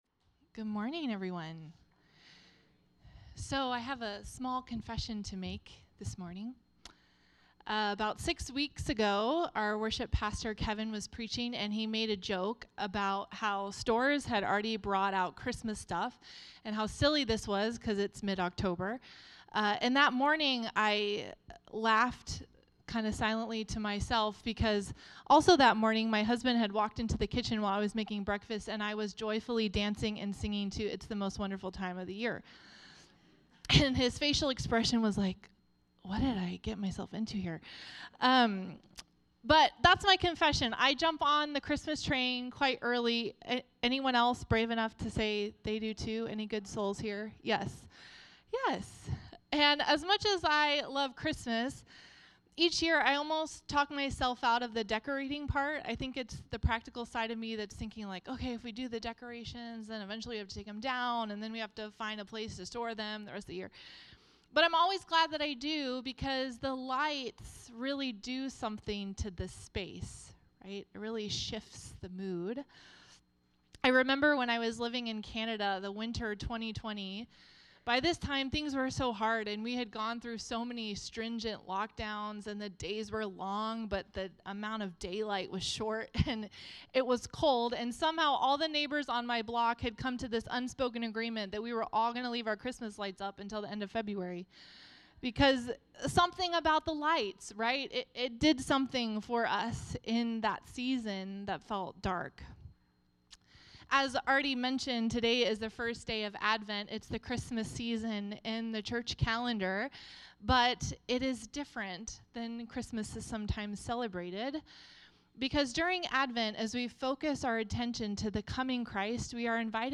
The River Church Community Sermons